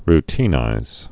(r-tēnīz, rtn-īz)